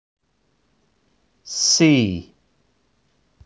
Alfabeto em Inglês Pronúncia a letra C
Alfabeto-em-Inglês-Pronúncia-a-letra-C-3.wav